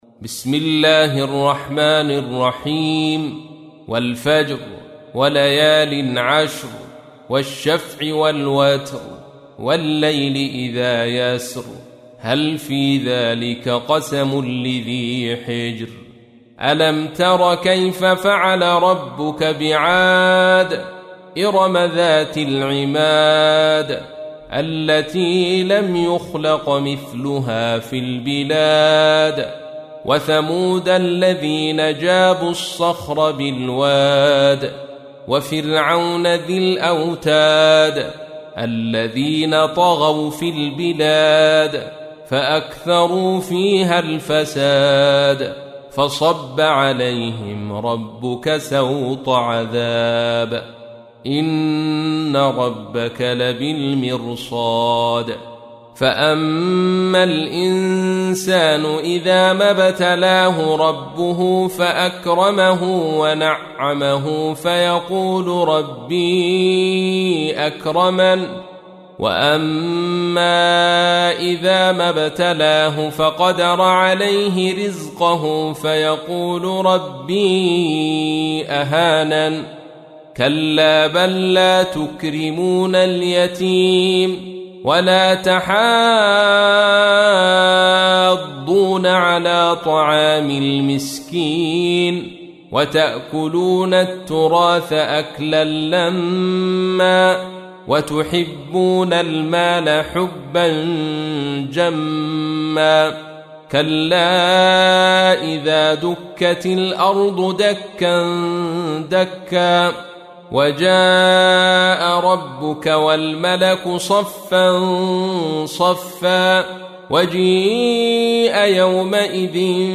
تحميل : 89. سورة الفجر / القارئ عبد الرشيد صوفي / القرآن الكريم / موقع يا حسين